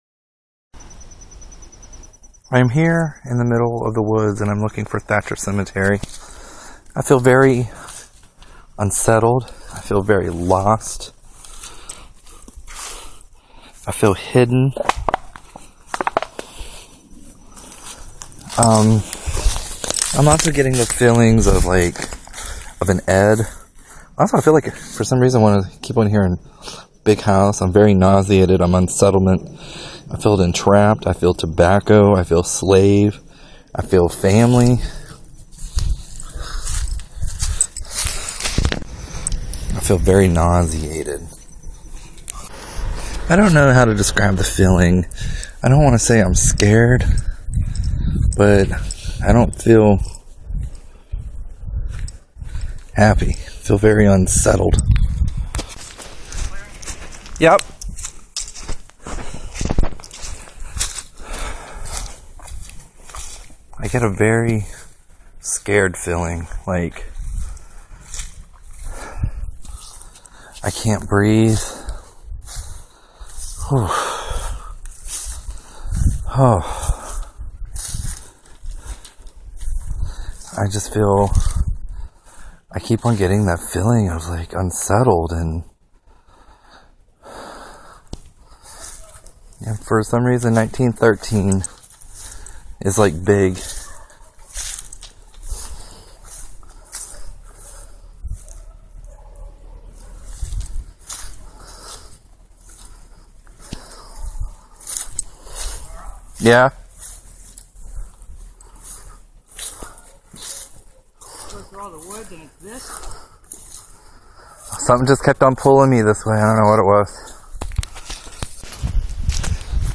Photographer and I approached him, and he immediately walked back down the path to the road speaking into his recorder, tallying up the feelings he was having about the old cemetery.
Creepy Bonus Audio
Pay attention to his breathing and his accent in the first three minutes, then notice the shift in the last few seconds:
If you didn’t hear the change in his voice, then listen again.